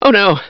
Download Half Life Oh No sound effect for free.